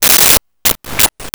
Computer Beeps 01
Computer Beeps 01.wav